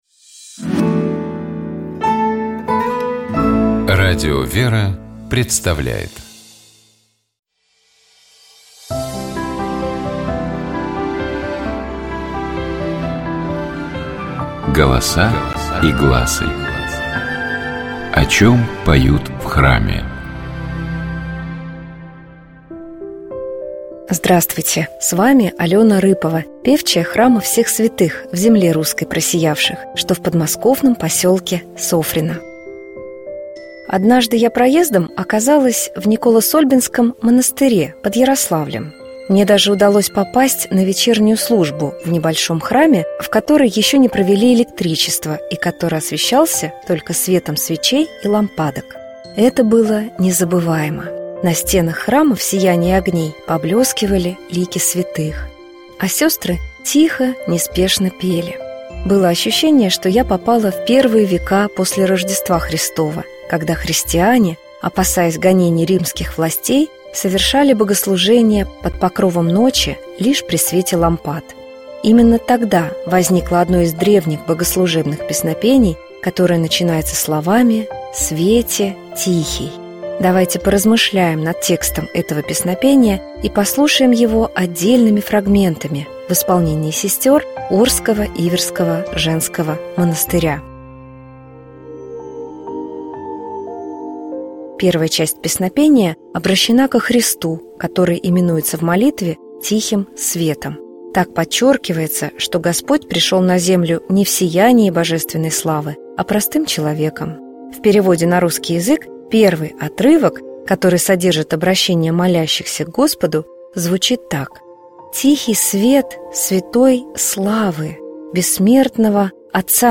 Это была Херувимская песнь, которая символизирует служение ангельских сил, херувимов, у Божьего Престола. Давайте поразмышляем над текстом Херувимской песни и послушаем её отдельными фрагментами в исполнении сестёр Орского Иверского женского монастыря.
Первая часть — протяжная, медленная, вторая — в более быстром темпе.